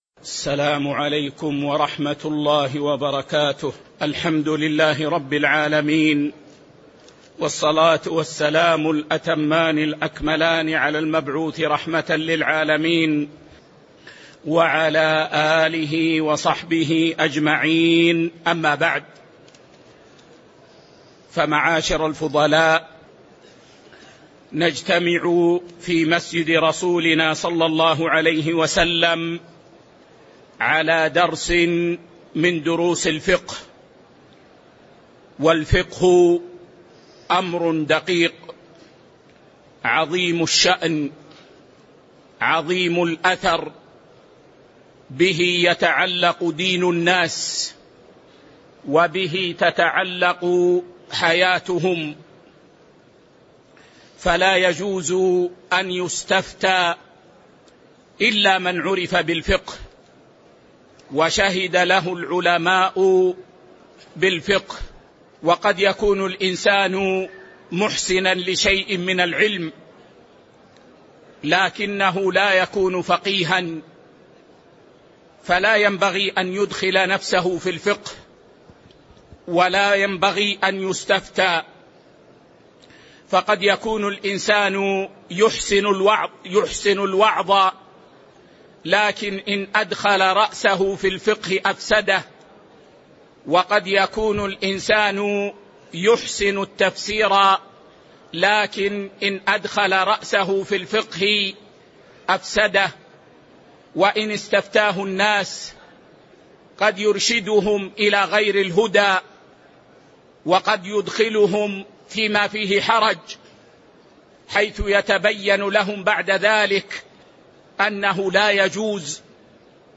تاريخ النشر ١٠ ربيع الأول ١٤٤٤ هـ المكان: المسجد النبوي الشيخ